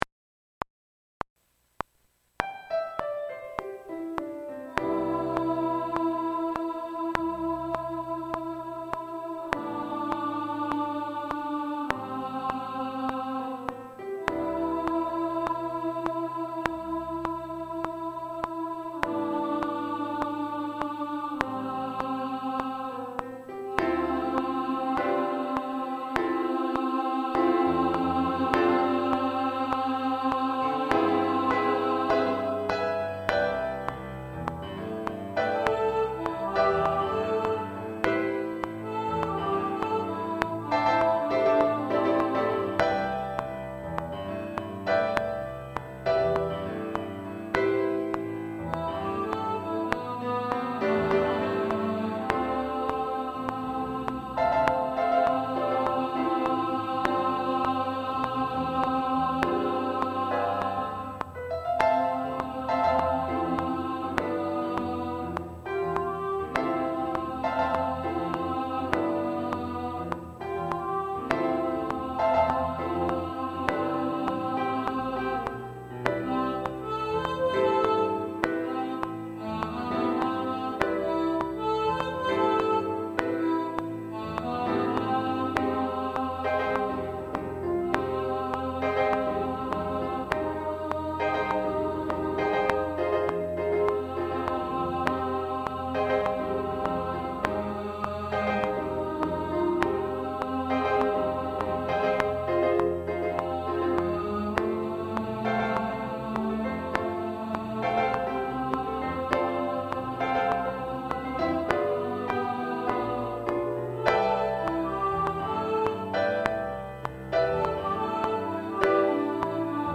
What’s Goin’ On Alto2 | Ipswich Hospital Community Choir